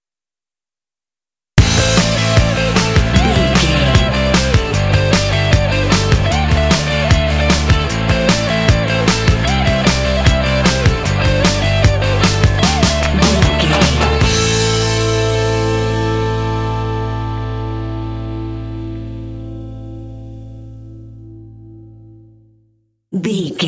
Fast paced
Aeolian/Minor
happy
bouncy
groovy
drums
electric guitar
bass guitar
indie